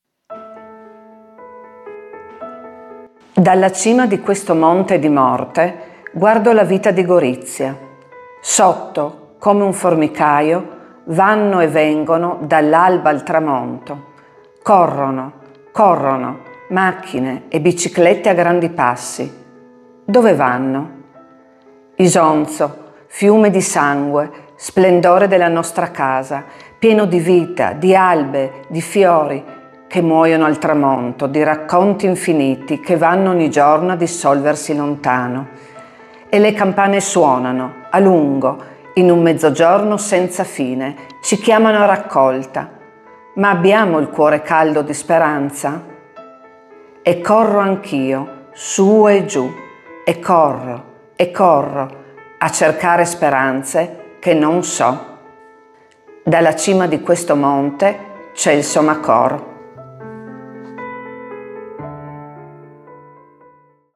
2_-_Poesia-Celso_Macor-_def.mp3